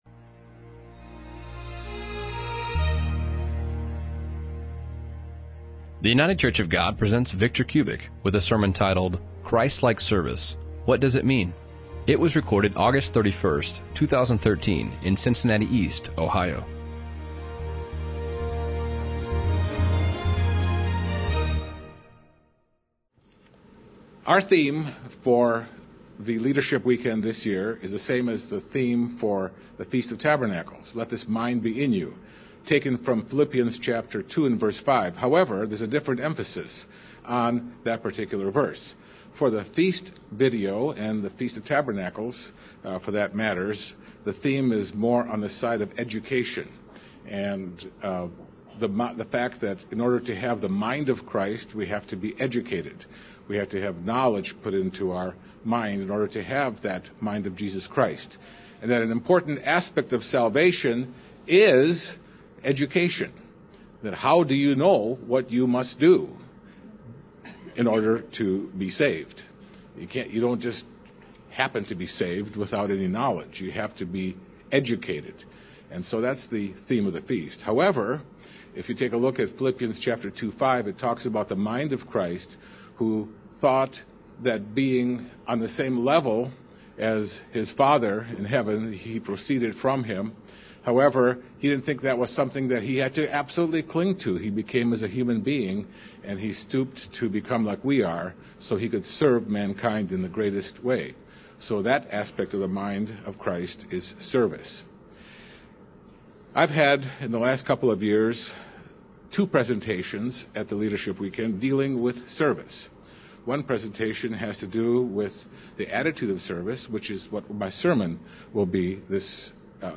This sermon addresses the attitude of service because God wants to educate us to become servants because it is a part of His nature.